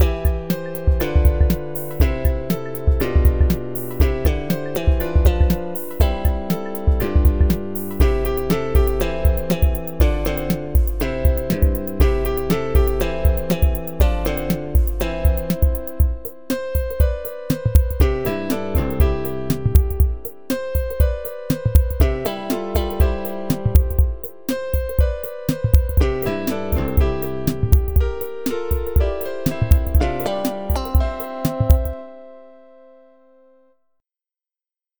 acompañamiento